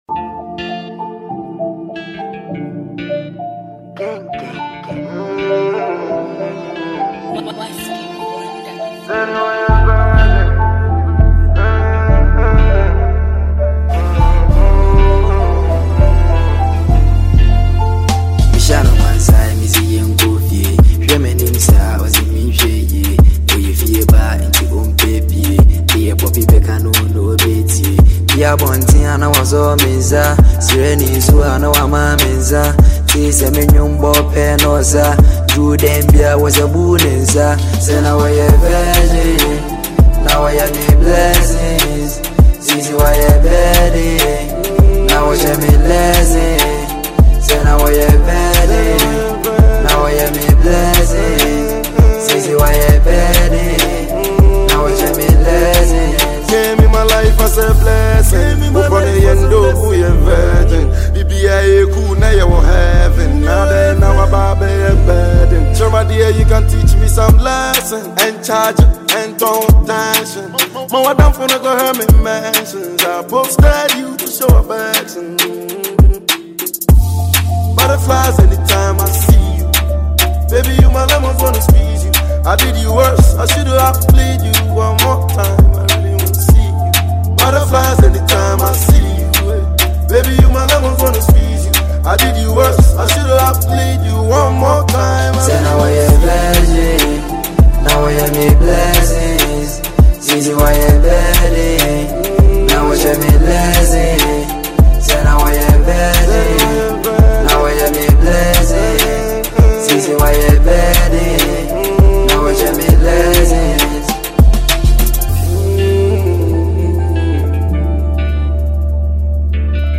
Genre: Drill / Kumerican